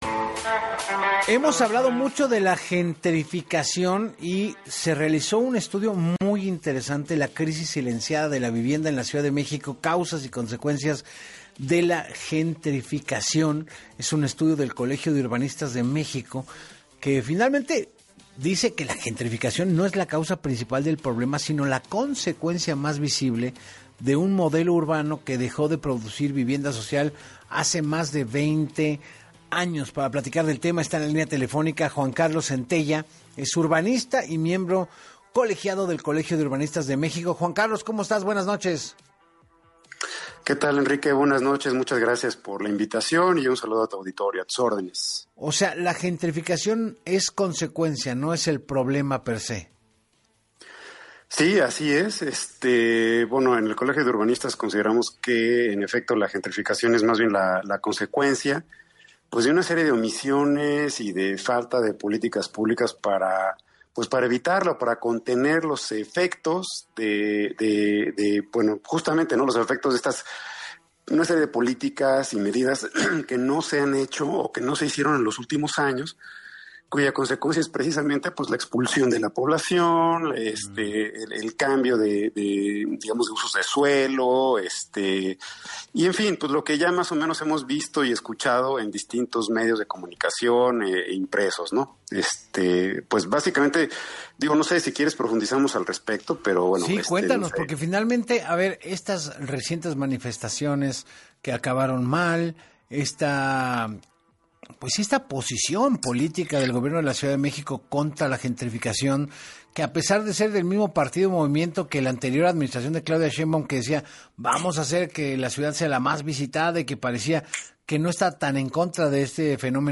En entrevista para Así Las Cosas